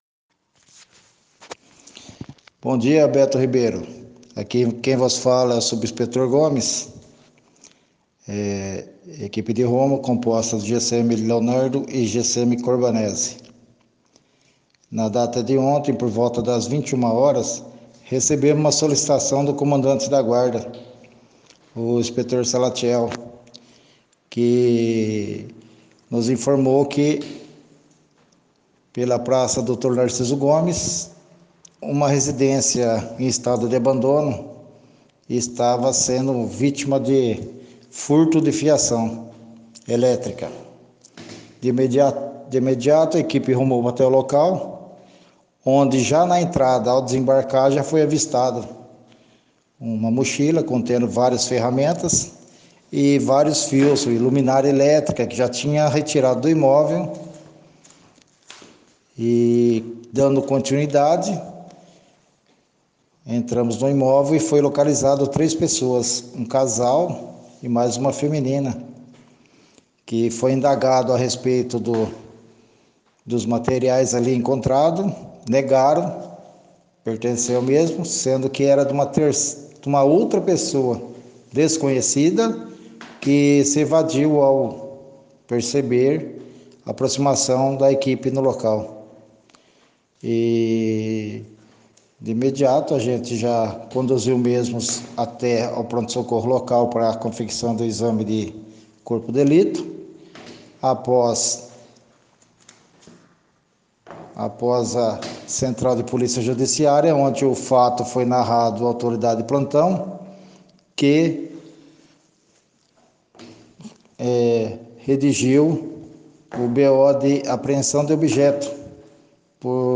Central de Polícia Judiciária